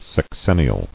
[sex·en·ni·al]